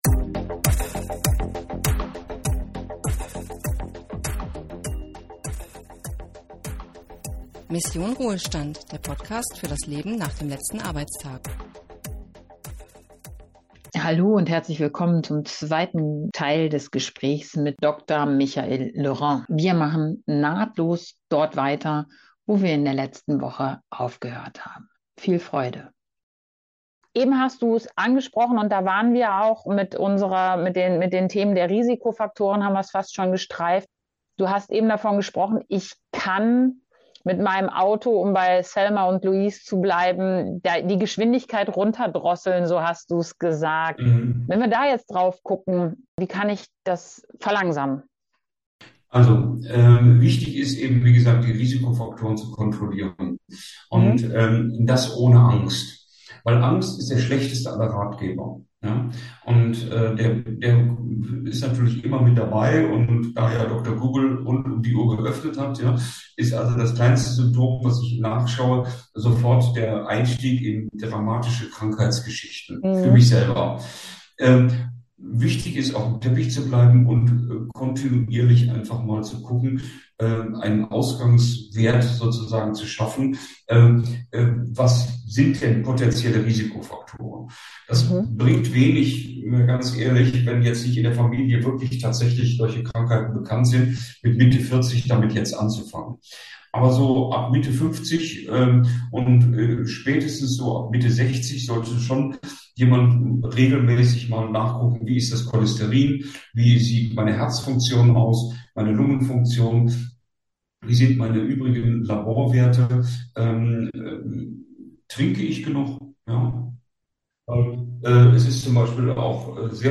In gut verständlicher Art und Weise klärt er auf und macht Mut.